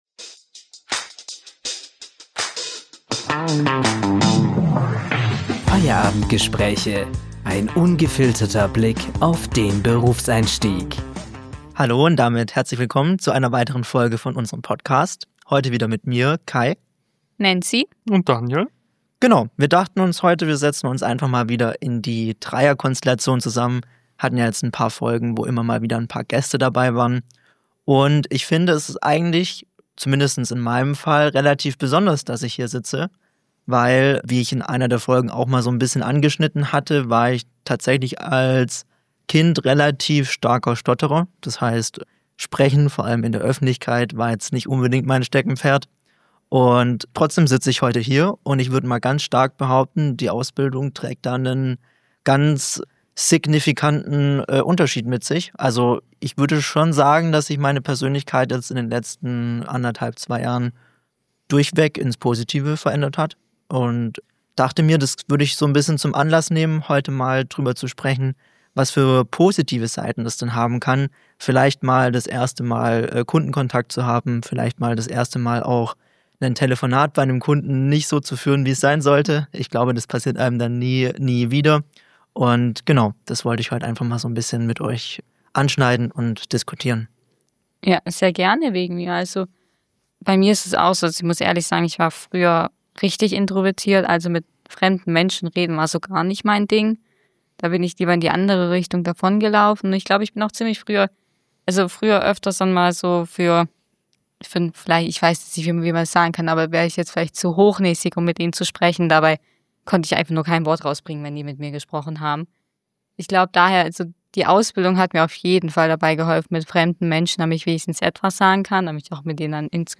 Ein extrem ehrlicher Talk über persönliche Hürden.